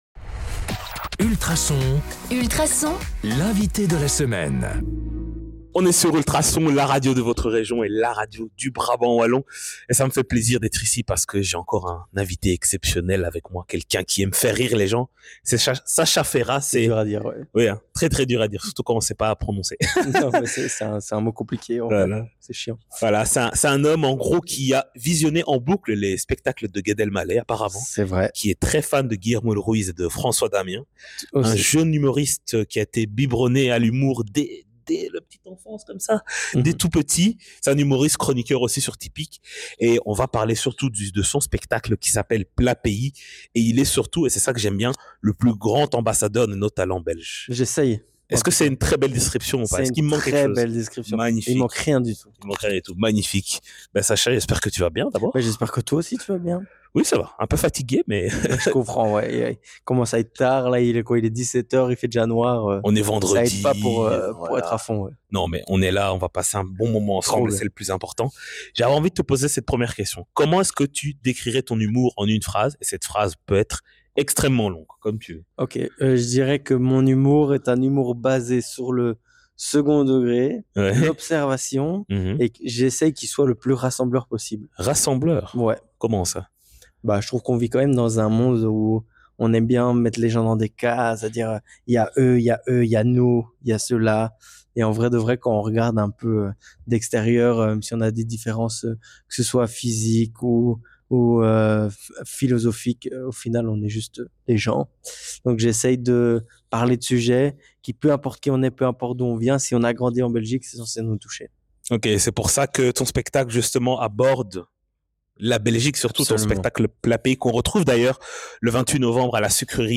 Dans ce chouette échange, il nous partage entre autres :